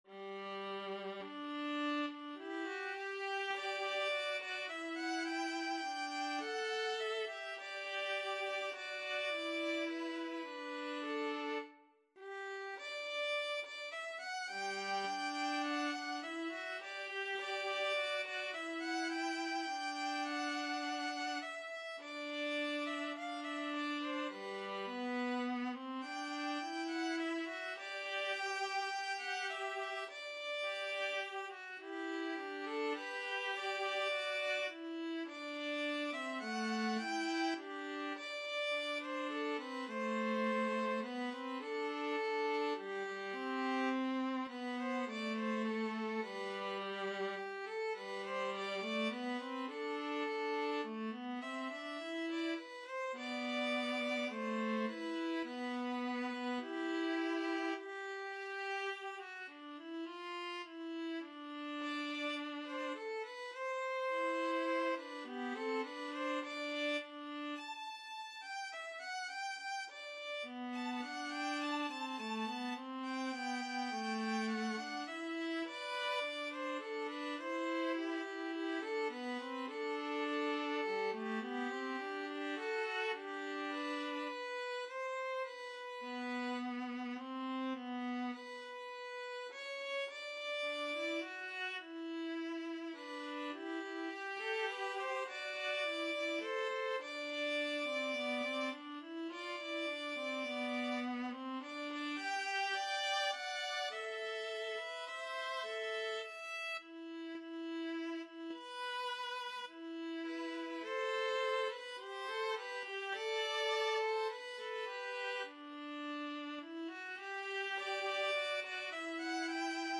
4/4 (View more 4/4 Music)
Allegro ( = 104-120) (View more music marked Allegro)
Classical (View more Classical Violin-Viola Duet Music)